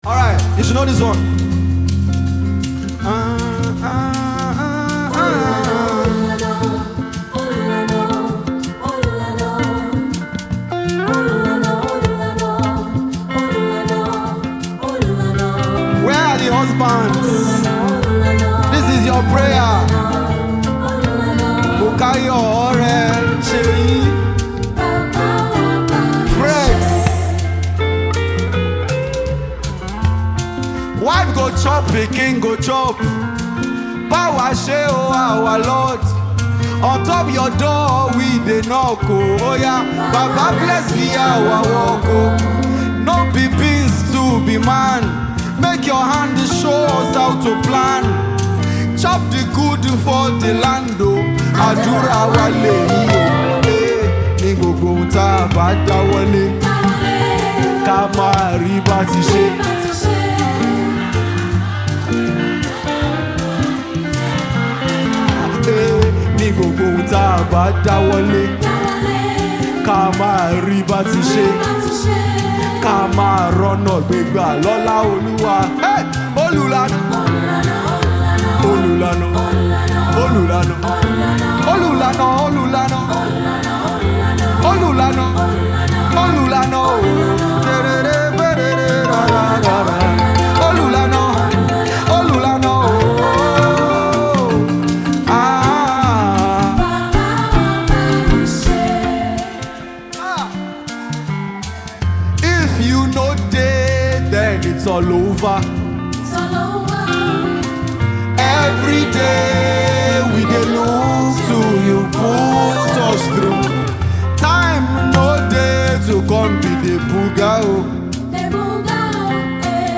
A live prayer. A heartfelt cry.
is a powerful live worship anthem
Recorded LIVE at Music Society Of Nigeria, Lagos.